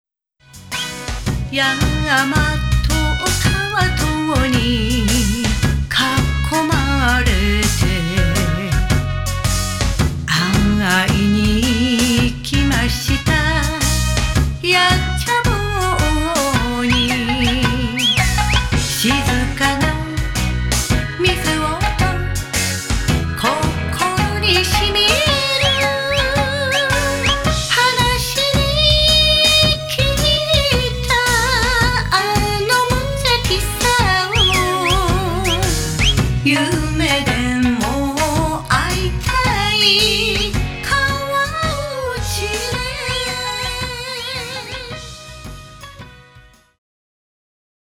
奄美歌謡